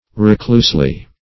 reclusely - definition of reclusely - synonyms, pronunciation, spelling from Free Dictionary Search Result for " reclusely" : The Collaborative International Dictionary of English v.0.48: Reclusely \Re*cluse"ly\, adv.
reclusely.mp3